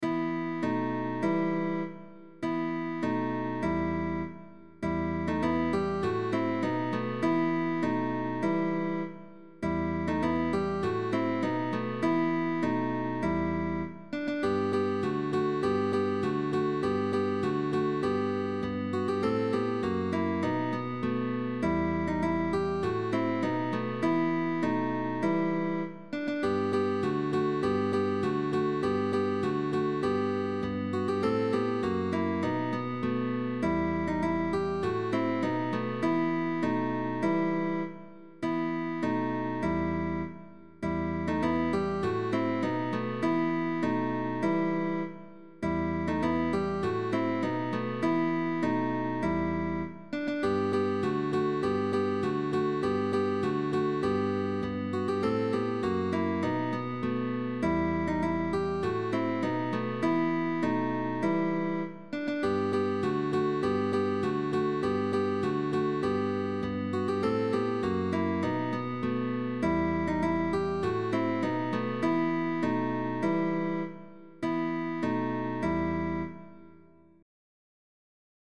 GUITAR TRIO
Spanish Carol by guitar trio sheetmusic.
Christmas